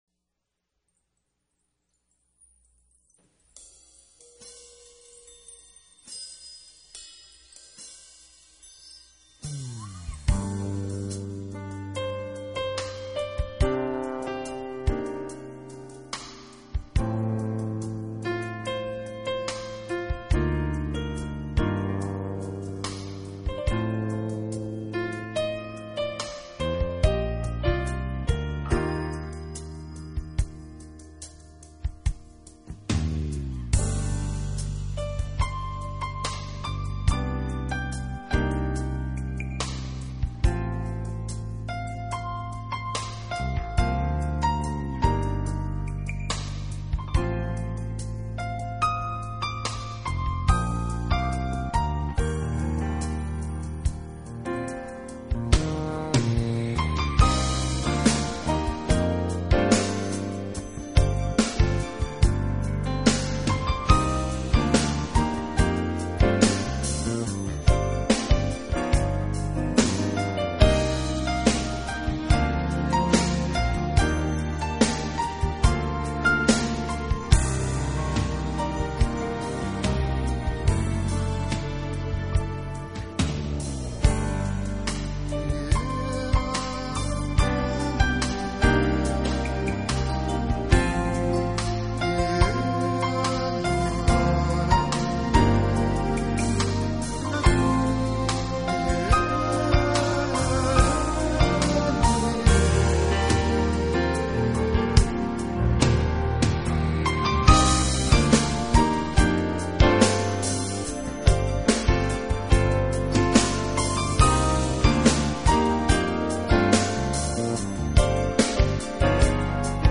音樂類型：Jazz
感覺又不同的地方，給人一份有隨風而逝的情懷，原因來自鋼琴的彈奏富有一份深度，加上
有雀鳥飛揚聲音作結尾的和應，令人有著不少的感觸，音樂意境和編排相當有新意，水準出